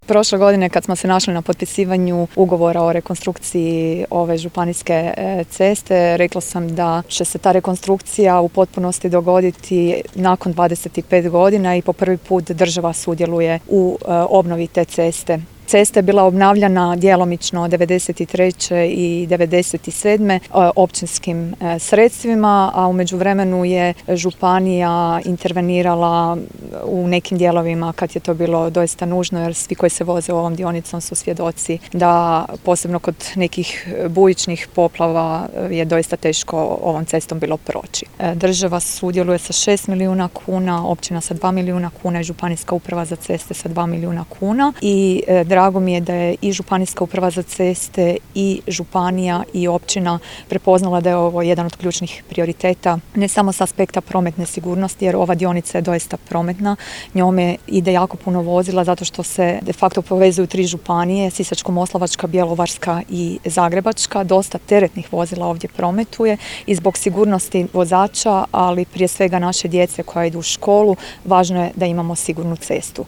FOTO | Svečano otvorena cesta Mala Ludina-Kompator-Mustafina Klada